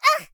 Worms speechbanks
ooff2.wav